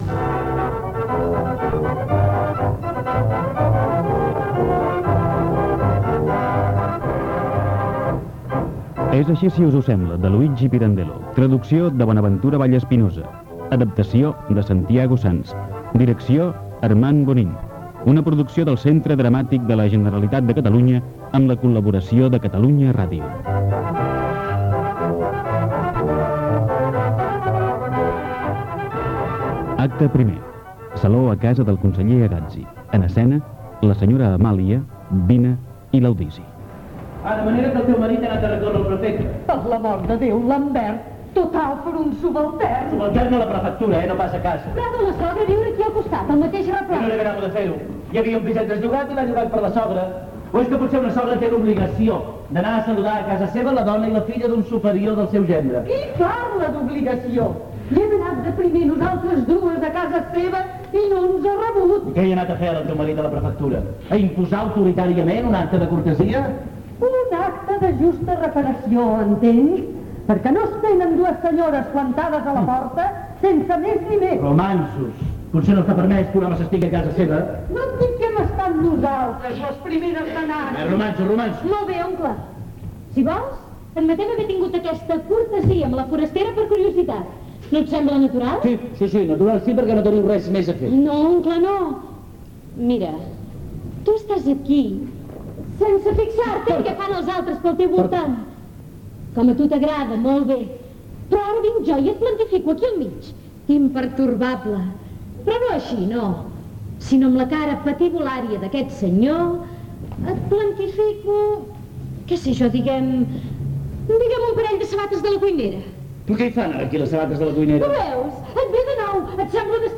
Careta del programa, el narrador situa l'acció i primeres escenes de l'obra Gènere radiofònic Ficció